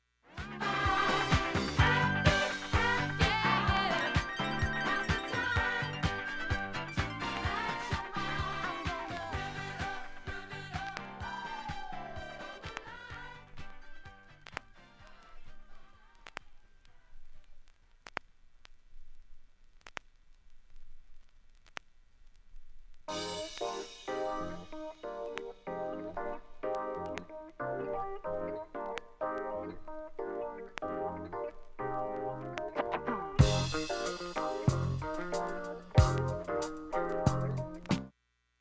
音質良好全曲試聴済み。
40秒の間に周回プツ出ますがかすかです。
ディスコ・ブギー